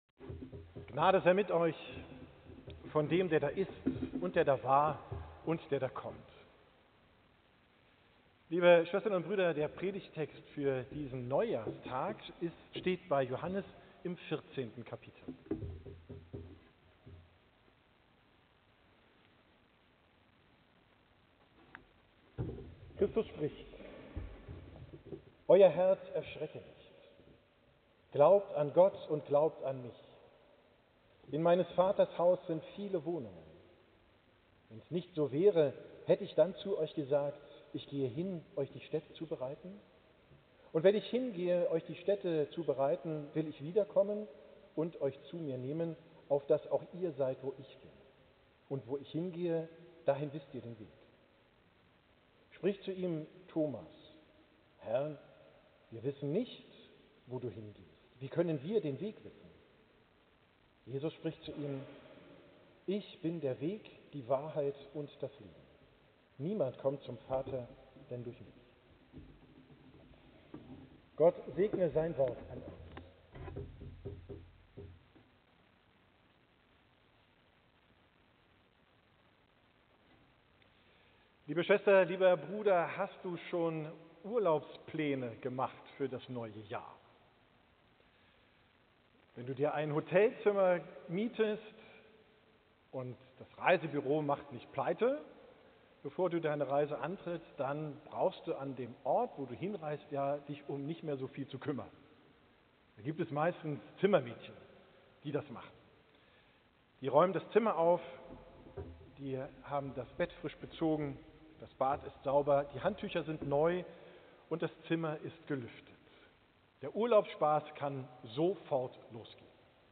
Hauptkirche St. Trinitatis Altona